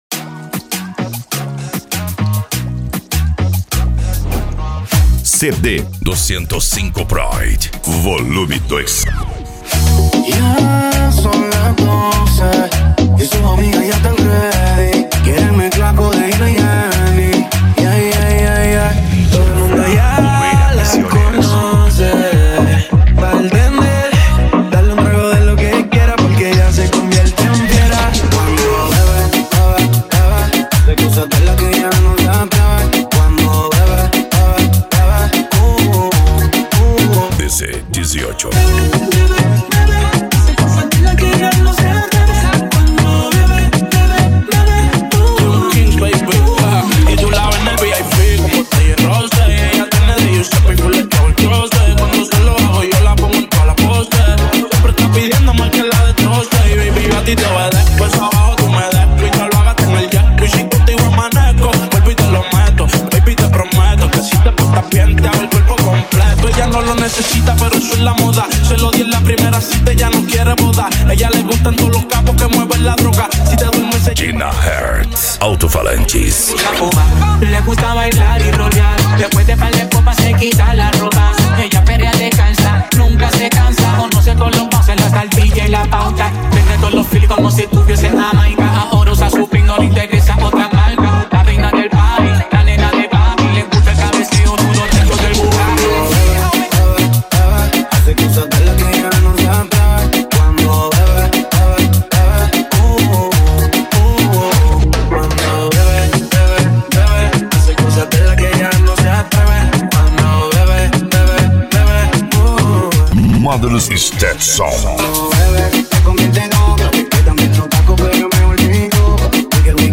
Bass
Remix